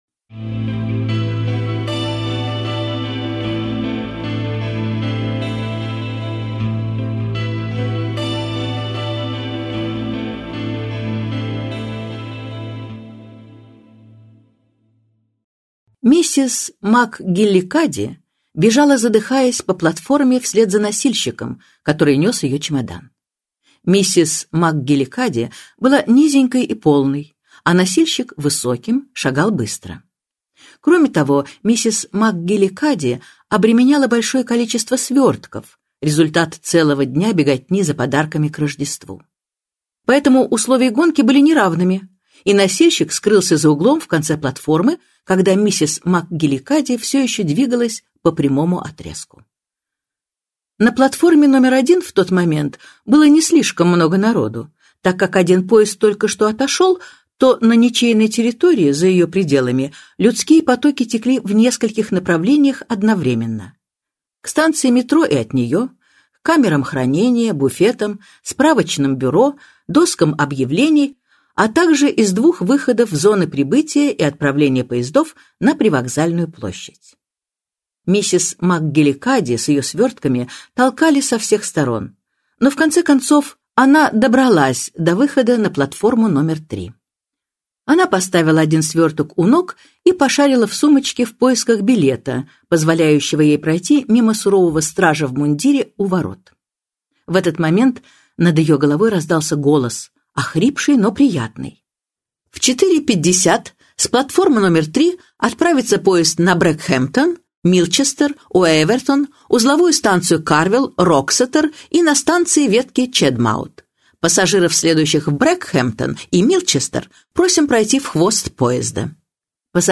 Аудиокнига В 4:50 с вокзала Паддингтон - купить, скачать и слушать онлайн | КнигоПоиск